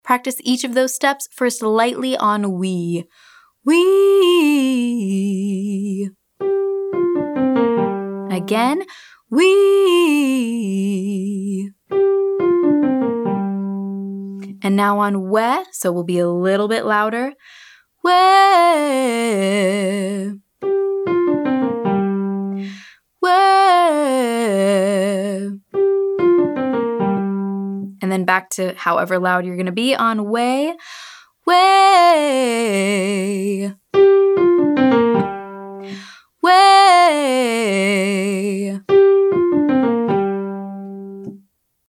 Improving Speed - Online Singing Lesson
Let’s practice this riff lightly on WEE, then medium on WEH, and loud on WAY.